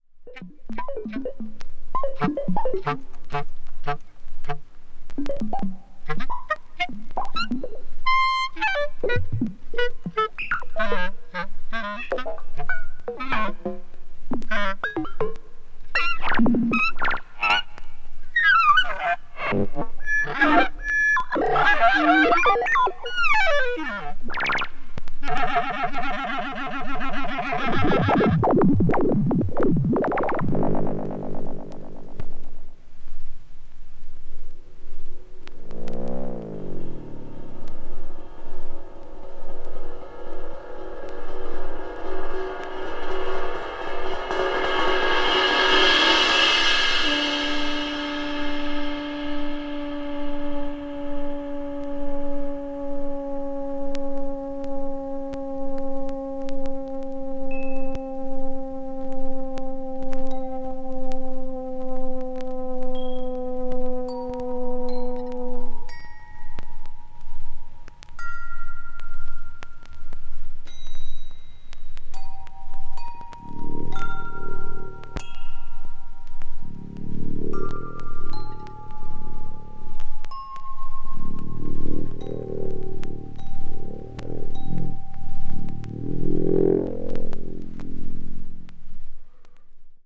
media : EX-/EX-(わずかにチリノイズが入る箇所あり)